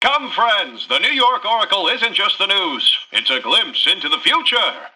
Newscaster_headline_01.mp3